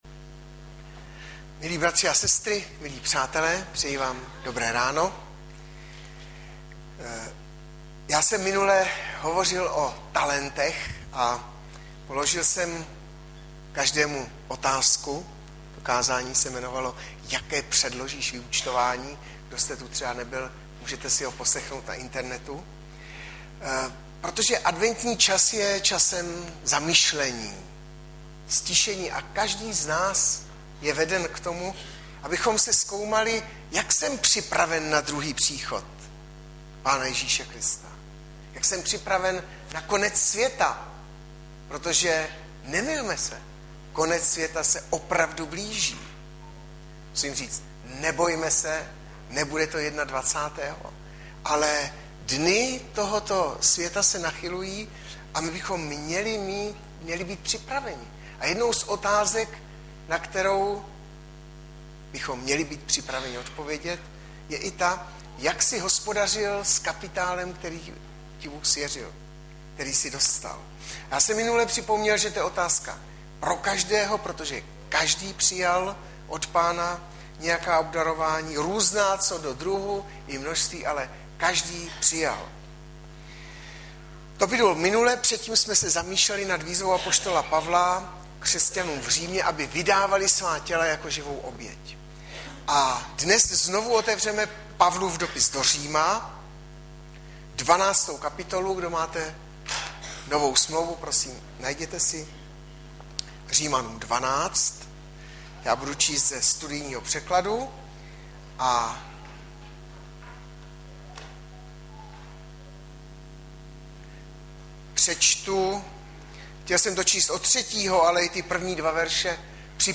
Hlavní nabídka Kázání Chvály Kalendář Knihovna Kontakt Pro přihlášené O nás Partneři Zpravodaj Přihlásit se Zavřít Jméno Heslo Pamatuj si mě  09.12.2012 - MÍRA VÍRY A OBDAROVÁNÍ - Ř 12,3 Audiozáznam kázání si můžete také uložit do PC na tomto odkazu.